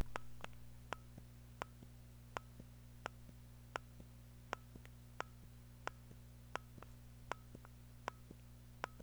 Hear are some short audio sound bytes / clips (all recordings were done at the same record volume level and same distance away from the microphone) of a stock Atari CX24 fire button being pressed and released over and over.
Stock CX24 Fire button WAV sound byte
You will hear the stock CX24 fire button sound has a muffled sound with a single click sound.
Stock CX24 fire button sound.wav